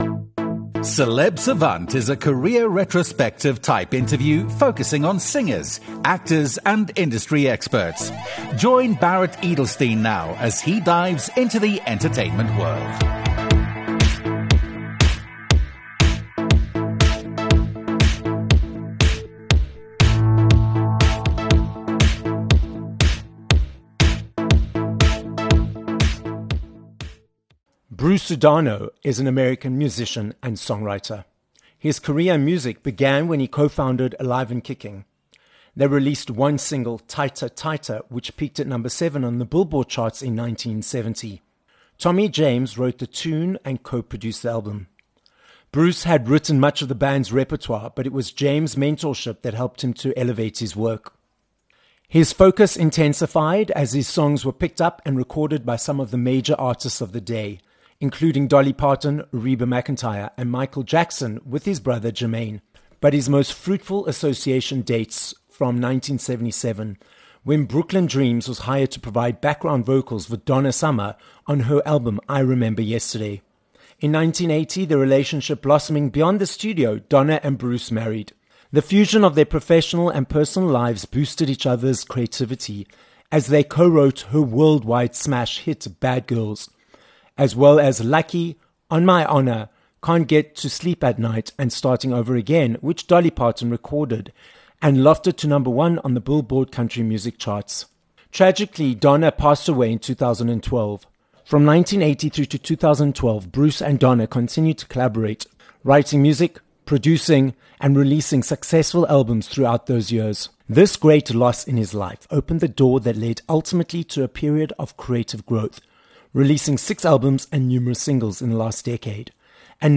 Bruce Sudano - an American singer, songwriter, and producer joins us on this episode of Celeb Savant.